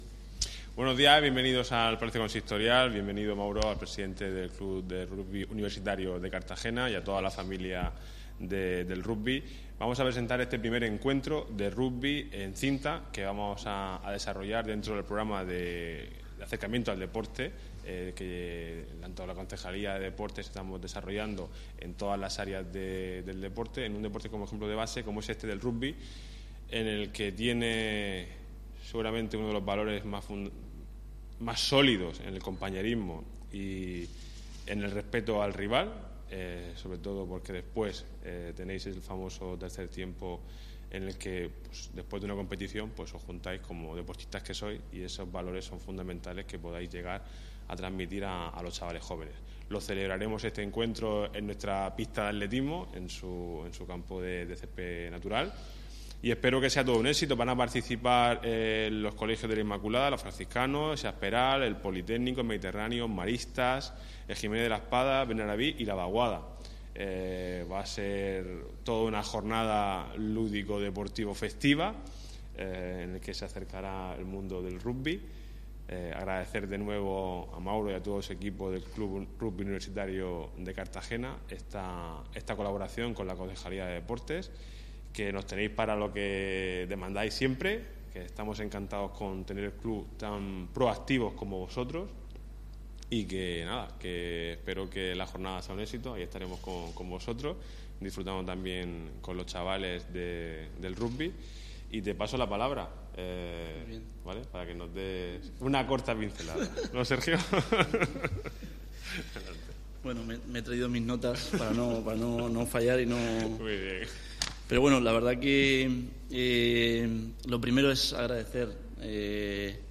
Enlace a Presentación del Encuentro Escolar de Rugby